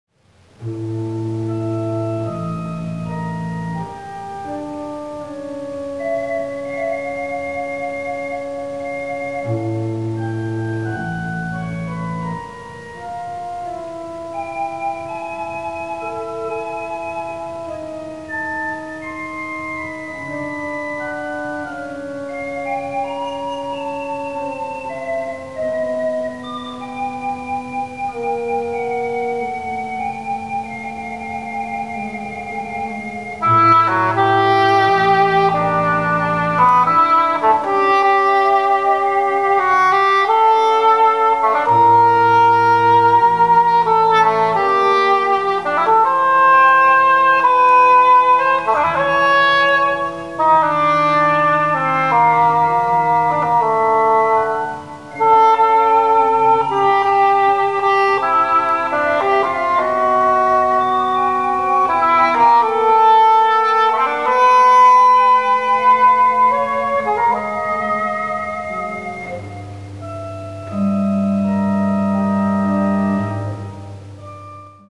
Englisch Horn
Orgel
Livemitschnitt, Stadtkirche Esslingen, 9.7.05